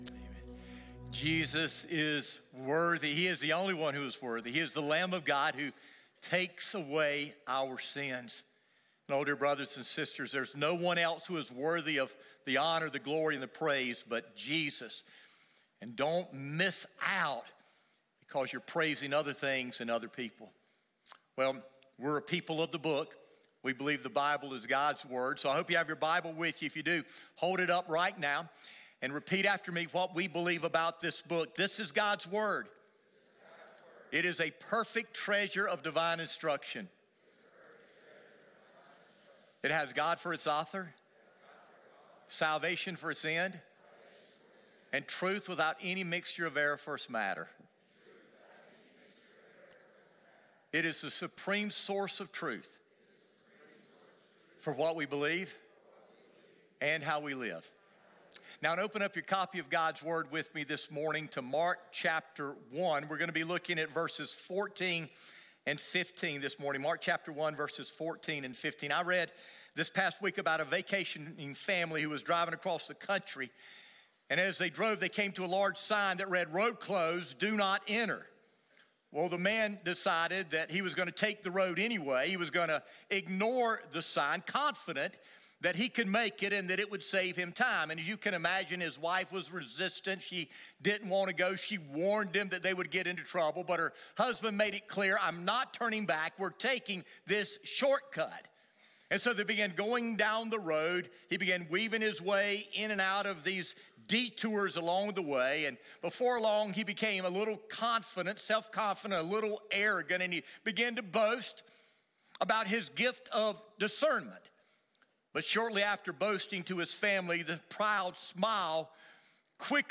Sermons | Northside Baptist Church